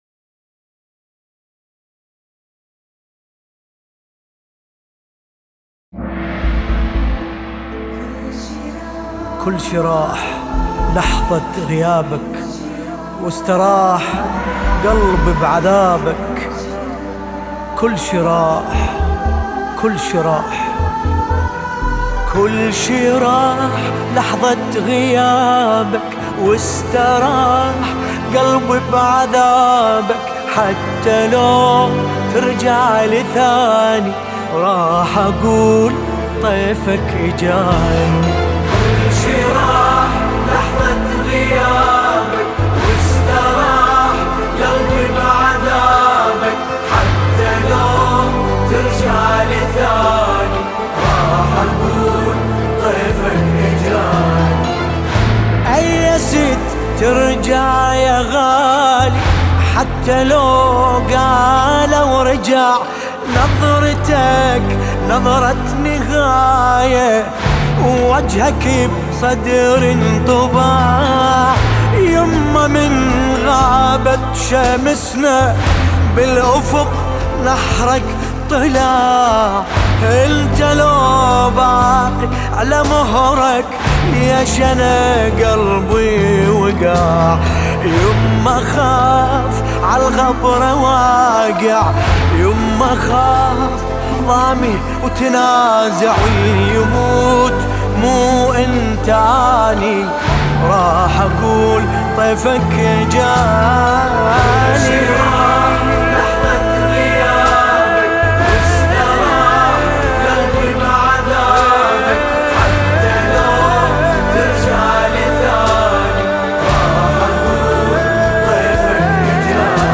سینه زنی
مداحی عربی مداحی استودیویی